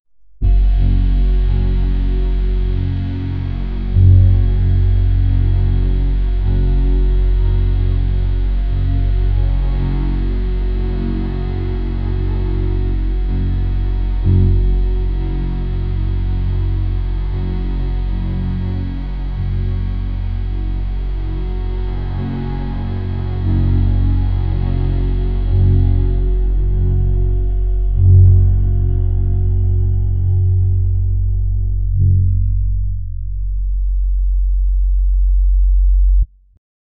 a rock band playing a song and then abruptly stopping causing amp feedback 0:15 Created Mar 8, 2025 5:04 PM Thriller, dark, tense building up until Second 11 then abrupt stop and continuing Dumper and calmer but still a Little creepy 0:37 Created Apr 16, 2025 9:19 AM
thriller-dark-tense-build-5mce7qy7.wav